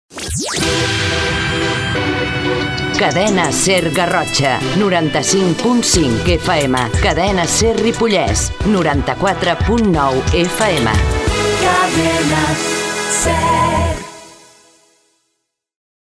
e905402b4c6abcd23c6e258f7bfa16c9f6b09a88.mp3 Títol Cadena SER Ripollès Emissora Cadena SER Ripollès Cadena SER Titularitat Privada estatal Descripció Identificació de Cadena SER Garrotxa i Cadena SER Ripollès.